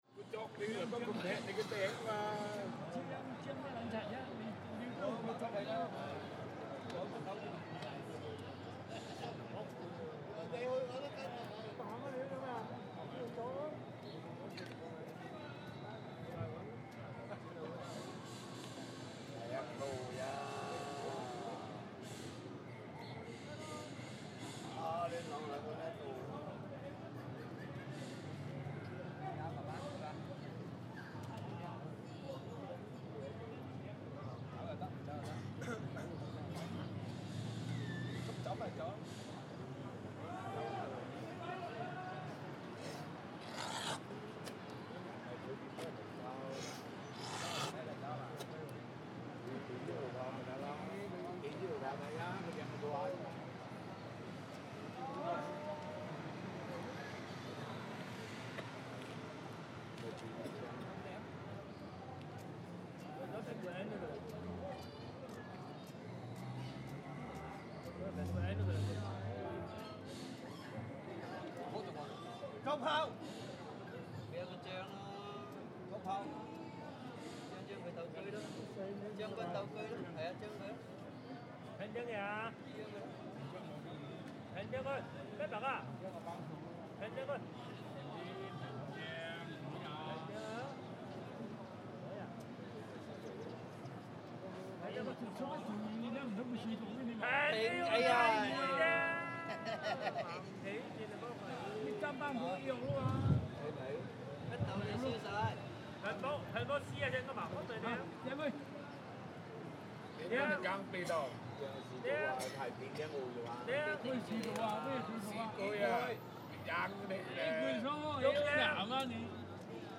una splendida piazza affollata da gruppi di cinesi, giovani ed anziani, intenti a giocare a scacchi (ovviamente cinesi), a un misconosciuto gioco di carte o a dama. Vociare e sputi si mischiano al traffico e ai suoni del quartiere finanziario, che si trova accanto, basta attraversare una strada.
sf_chinatown_chess.mp3